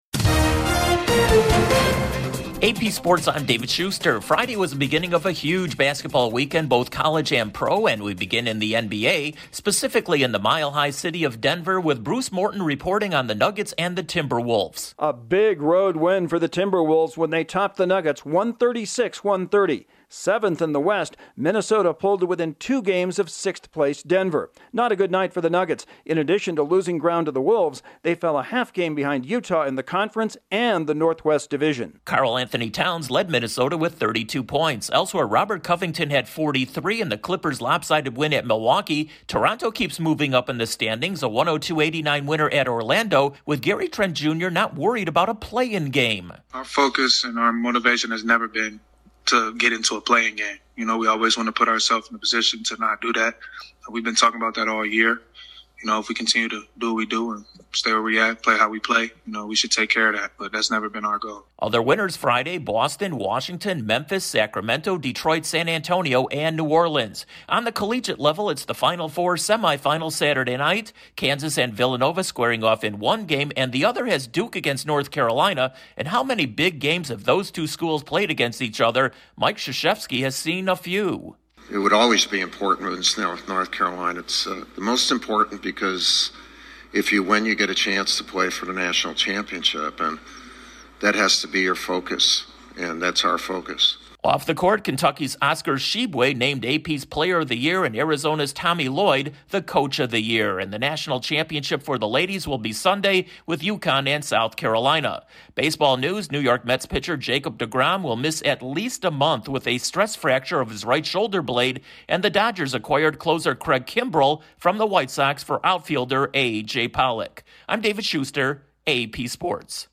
The Timberwolves get a big win as the Raptors climb the Eastern Conference standings, the men's Final Four is on tap for Saturday, the AP doles out its two major men's basketball awards and the Mets lost their ace for at least a month. Correspondent